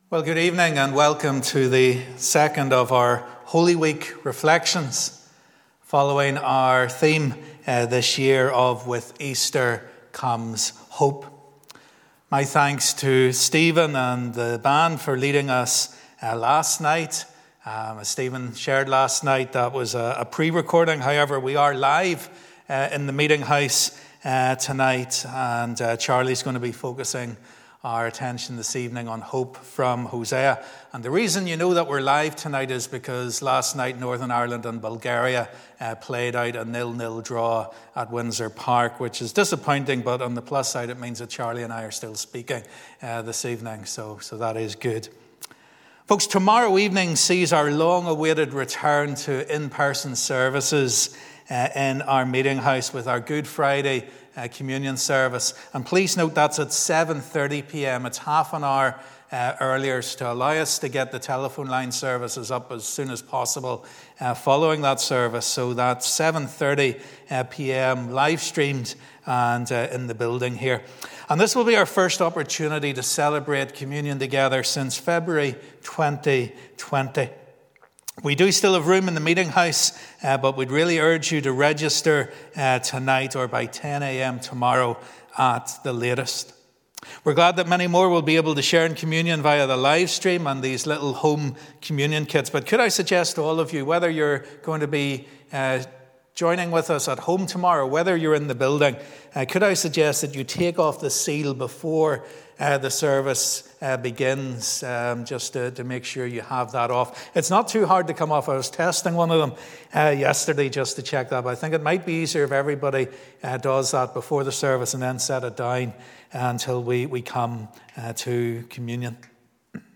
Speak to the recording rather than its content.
This evening is the second of our Holy Week services based on the theme 'With Easter Comes Hope'. Join us as discover hope in Hosea.